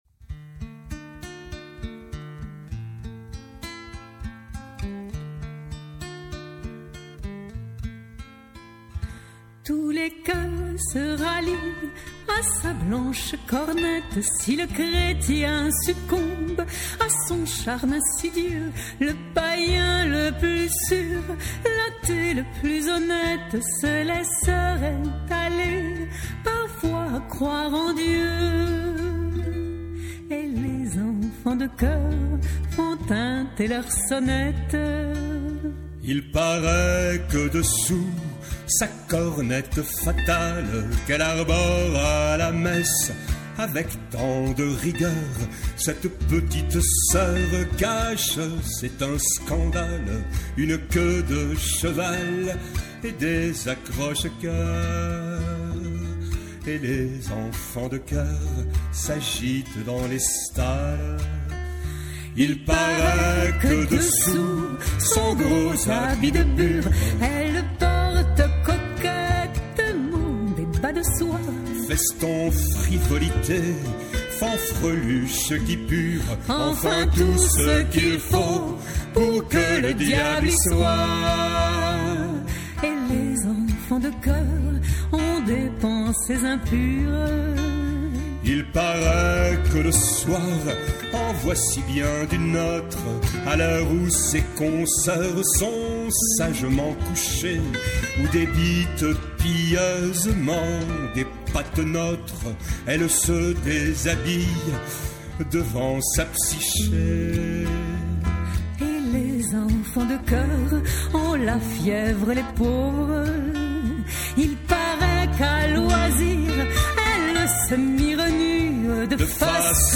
contrebasse
à deux voix